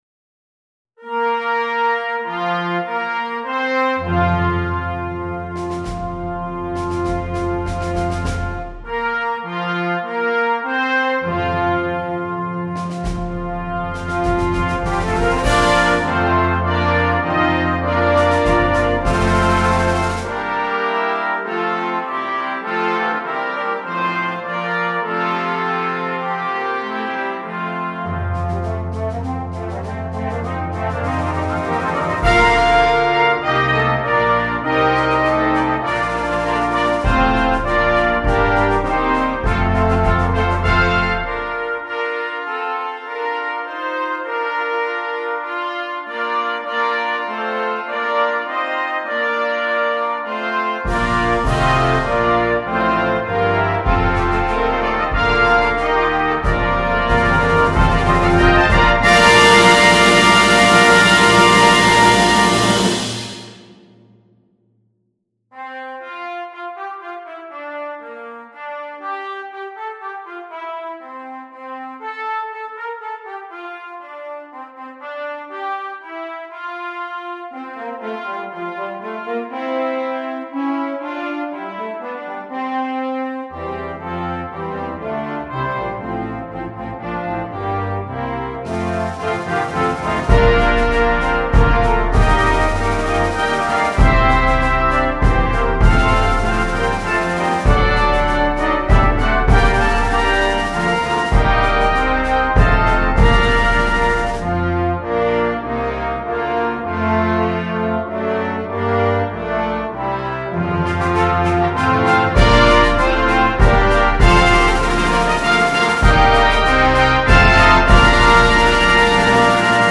2. Brass Band
Full Band
Christmas Music
– Eb Soprano Cornet
– 3 Solo Cornets
– the 3 Trombones